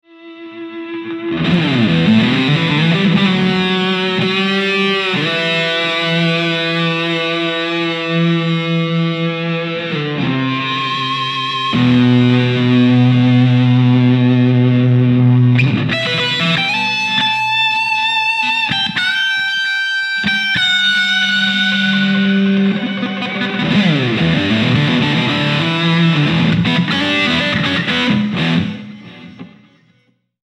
Peale modi vägagi tõsine hi gain pedaal.
siin klipp moditud mt-2 st ja võin kinnitada, et asi saundibki raisk nii hästi.